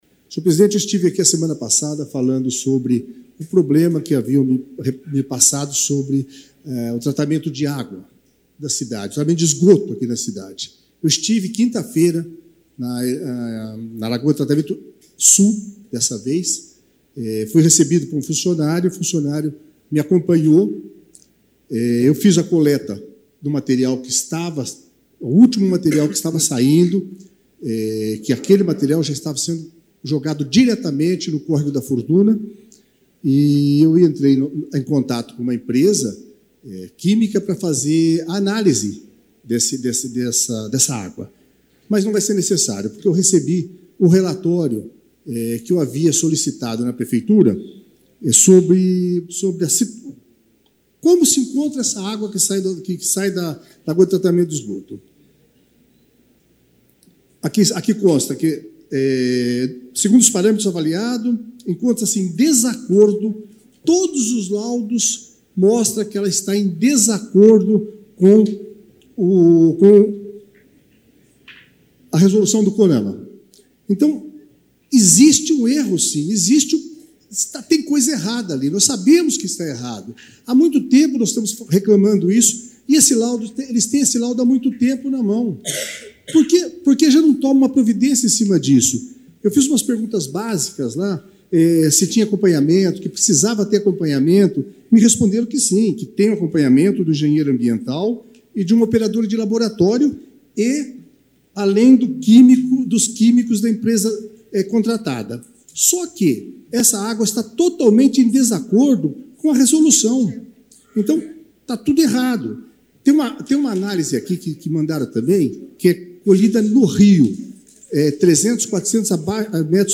Na sessão ordinária da noite de ontem na Câmara Municipal, o vereador Beto Coelho denunciou que os responsáveis pelo tratamento de esgoto da ETE Sul está em desacordo com as regras indicadas pelo Saneamento Básico.
Ouça a denúncia feita pelo vereador Beto Coelho na tribuna da Câmara.